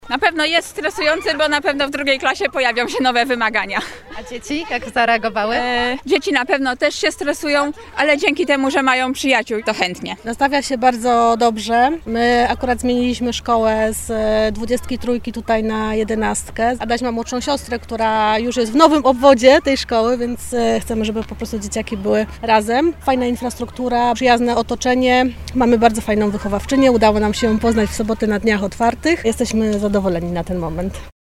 Wrzesień wiąże się z dużymi wydatkami. Pytamy rodziców, jak organizują powrót pociech do szkół.
04_rodzice-1.mp3